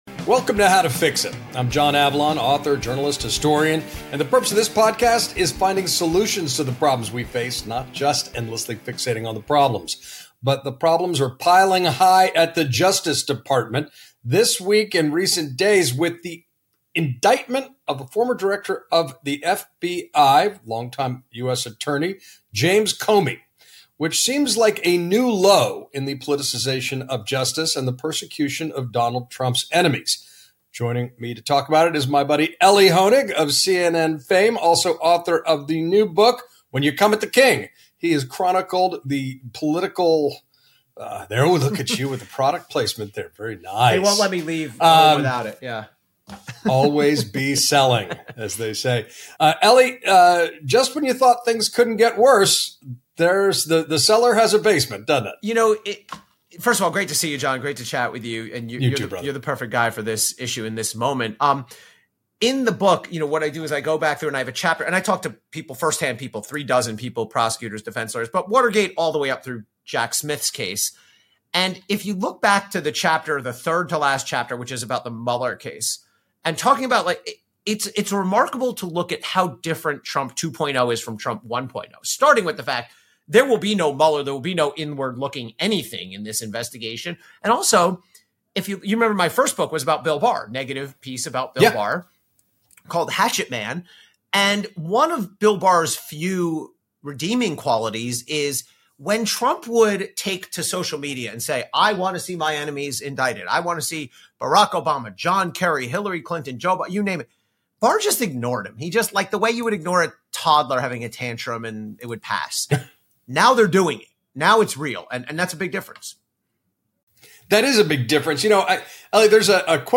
John Avlon sits down with CNN’s Elie Honig to unpack Trump’s escalating war on the rule of law.